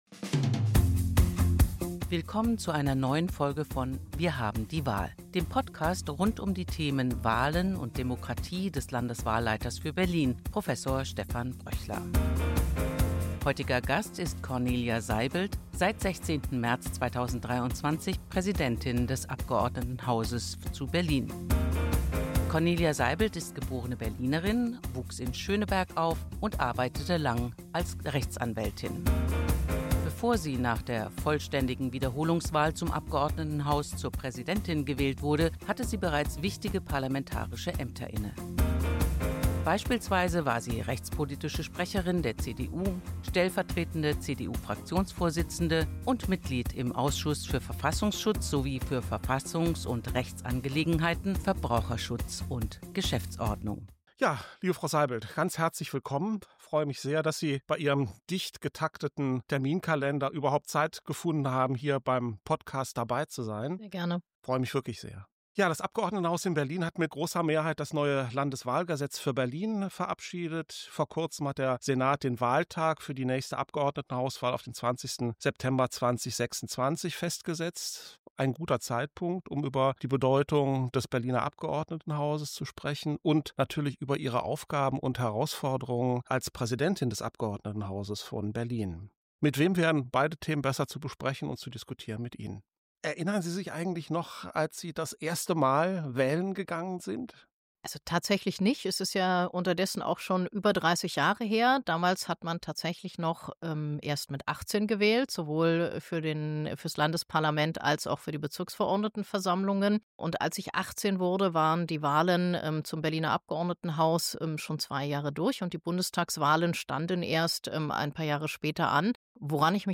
In Folge 11 des Podcast „Wir haben die Wahl“ spricht Landeswahlleiter Prof. Dr. Stephan Bröchler mit Cornelia Seibeld, seit 16. März 2023 Präsidentin des Abgeordnetenhauses von Berlin.